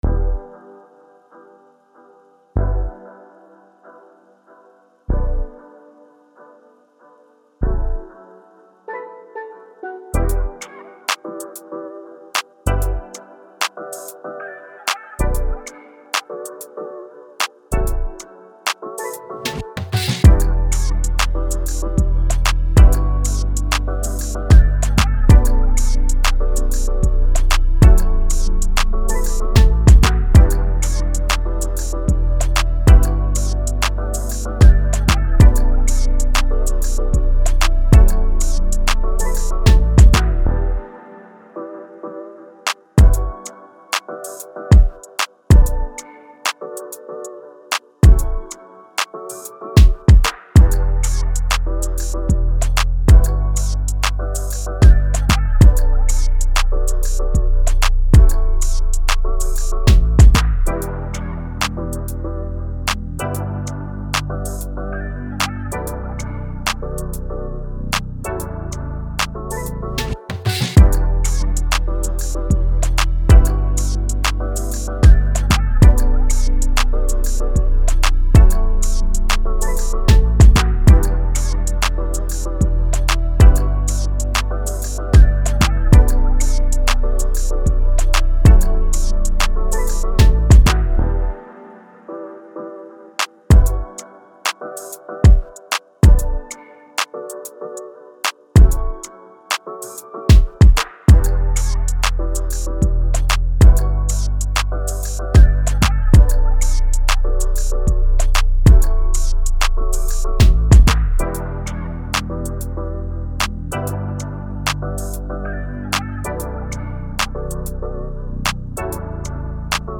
Reggae
B min